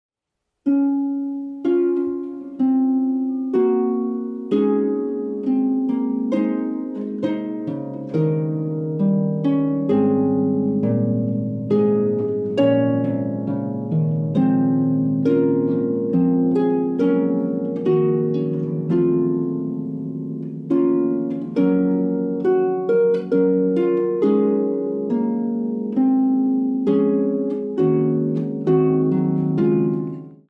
solo pedal harp